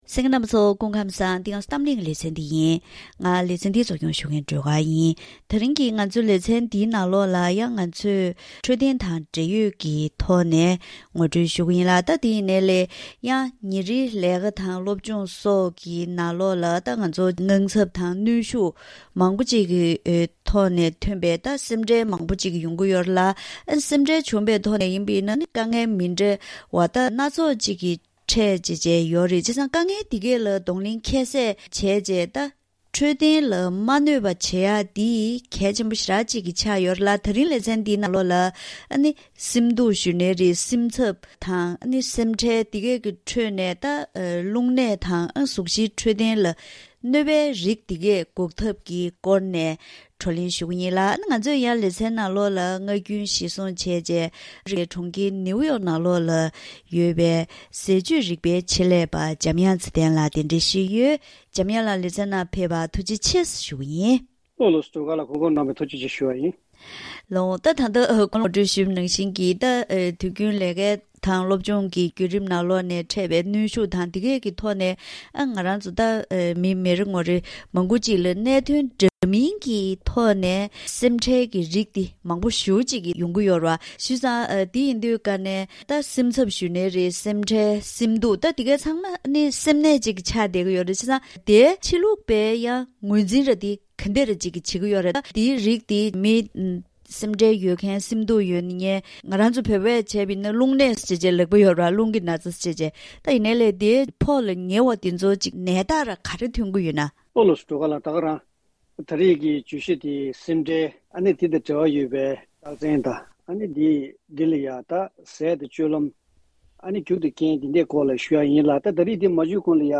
གནས་སྟངས་འདིའི་རིགས་ལ་ཟས་བཅུད་དང་འཚོ་བའི་གོམས་གཤིས་ལ་བསྒྱུར་བ་བཏང་པའི་ཐོག་ནས་འགོག་ཐབས་སྐོར་ལ་ཟས་བཅུད་རིག་པའི་ཆེད་ལས་པ་དང་ལྷན་དུ་བཀའ་མོལ་ཞུས་པ་ཞིག་གསན་རོགས་གནང་།